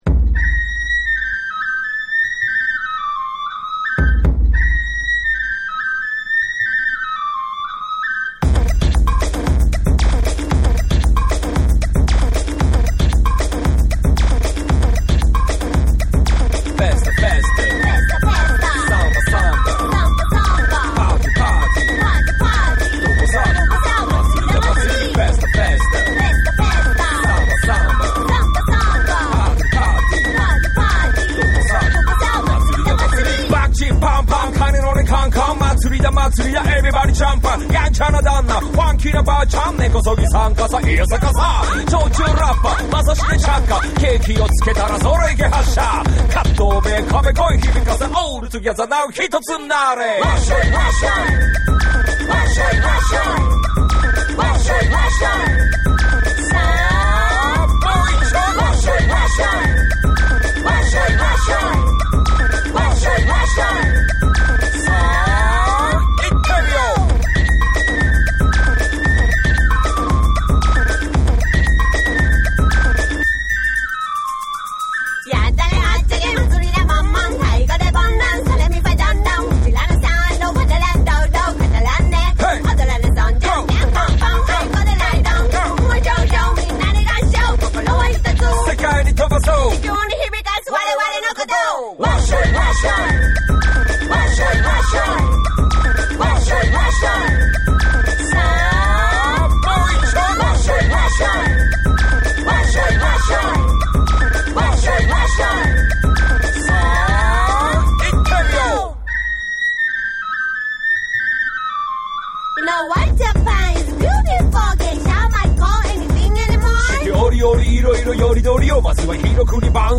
土着的かつお祭り感溢れるテクニカルなラップと表情豊かな女性ボーカルが融合するオリジナリティ溢れる世界観が話題です。
篠笛と太鼓にビートが絡む、ザ・お祭りサウンド
JAPANESE / BREAKBEATS / ORGANIC GROOVE